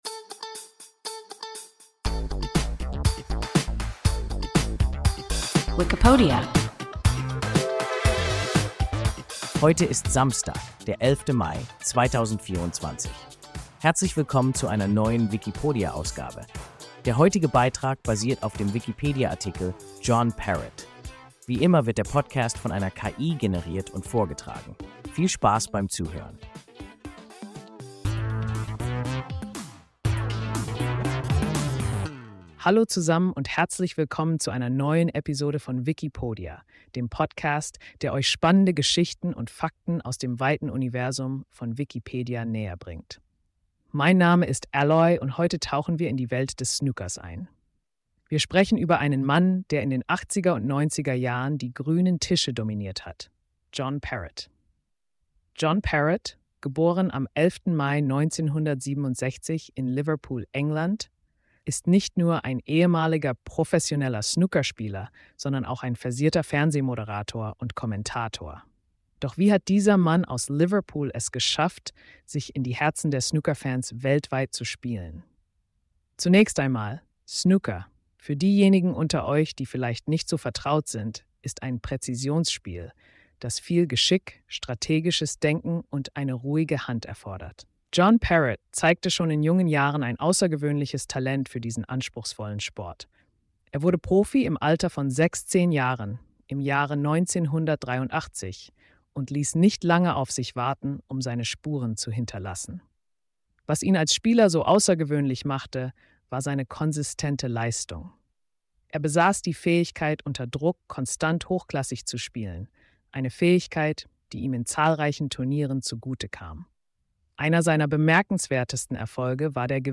John Parrott – WIKIPODIA – ein KI Podcast